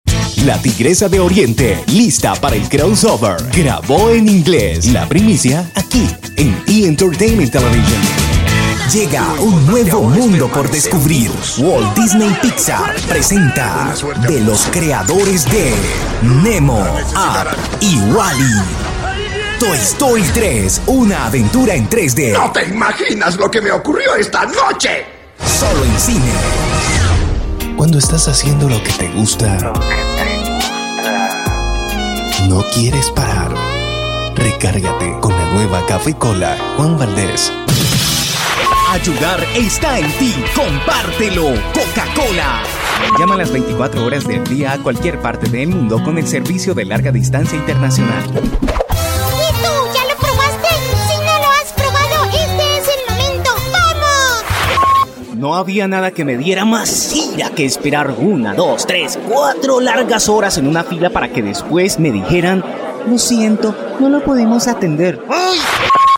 Cuento con un estudio de grabación profesional, tengo diferentes micrófonos como: AKG 414 XII, AKG perseption 200, Audiotechnica AT3035, Baby bottle, mezcladora Allen & Heath GL3300, preamp Avalon 737 sp, SSL XLogic alpha channel, focusrite twinpack, presonus, beheringer, utilizo el software Protools LE y audition.
Locutor comercial: Voiceover.
Sprechprobe: Werbung (Muttersprache):